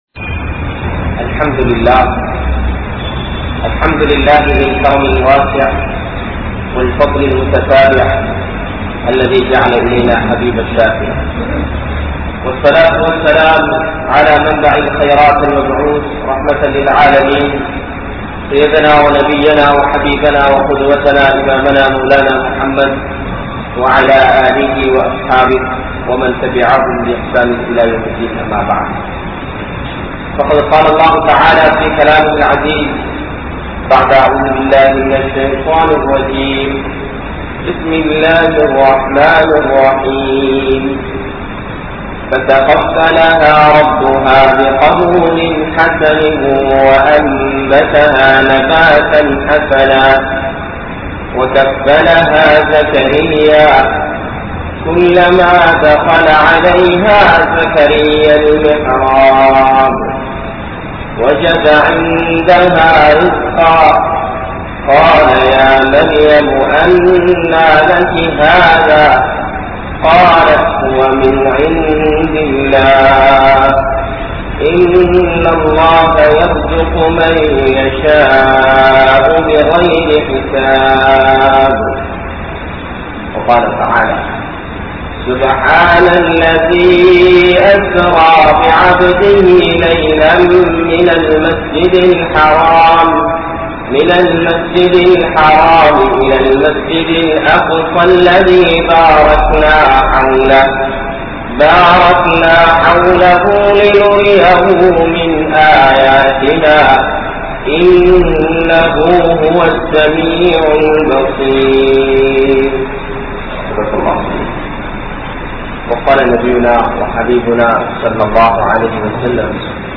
Kulanthai Valarpum Palastine Samoohamum (குழந்தை வளர்ப்பும் பலஸ்தீன் சமூகமும்) | Audio Bayans | All Ceylon Muslim Youth Community | Addalaichenai